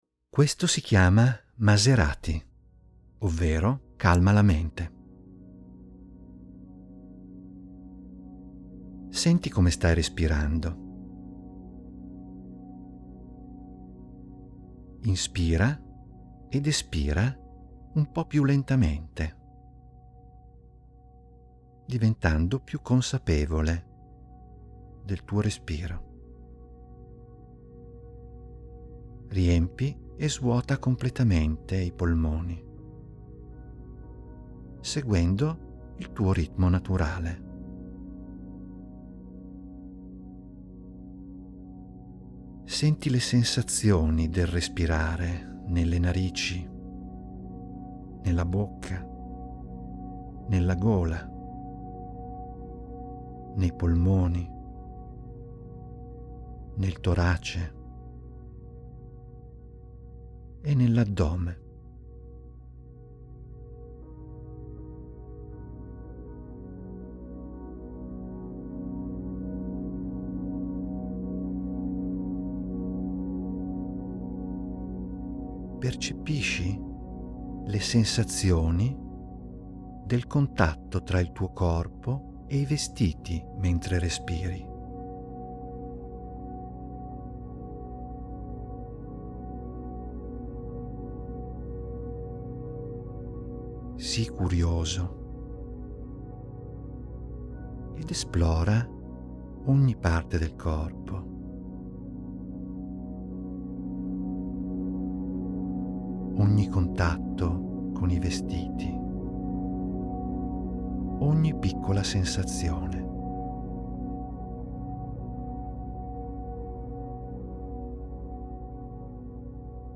In questa sezione trovi degli audio che ti guidano a svolgere delle mini-meditazioni di due minuti.